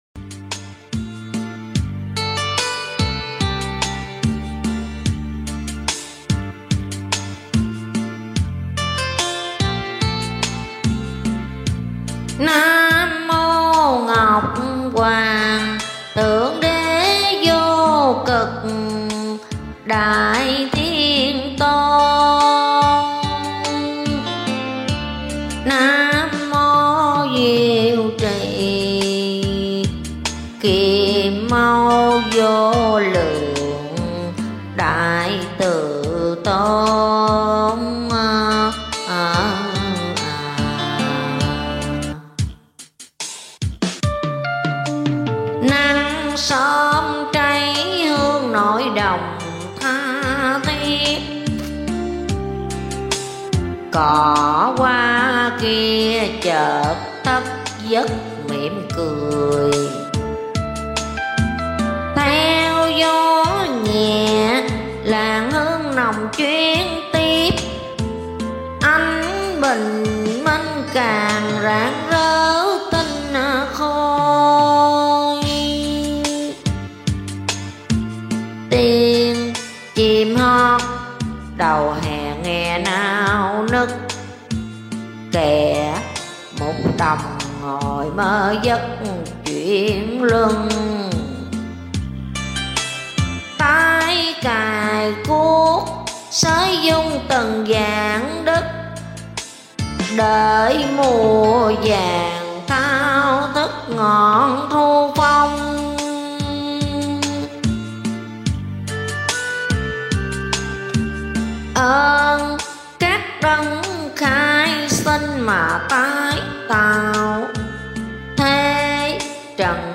Hát Kinh - Lục Mẫu Giáng Đàn - Vị Lai Pháp